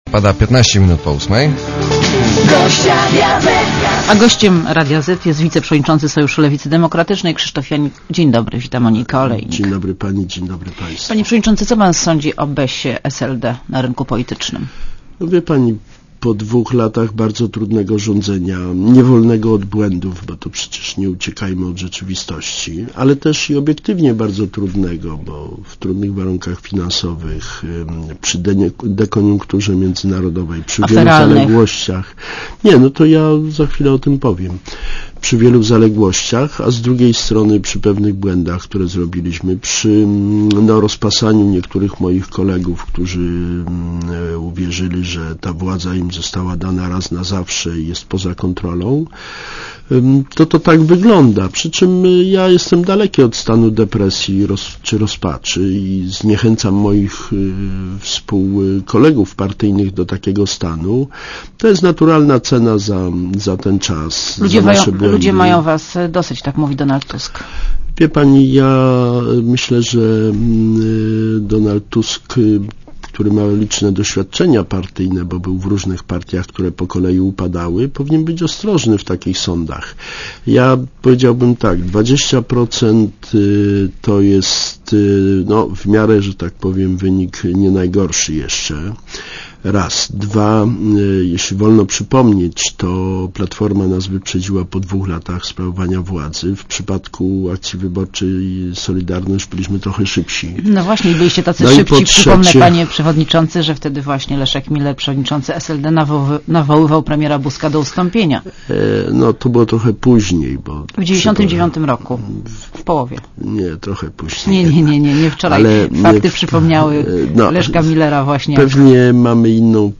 Posłuchaj wywiadu (3.0 MB) : Gościem Radia Zet jest wiceprzewodniczący Sojuszu Lewicy Demokratycznej.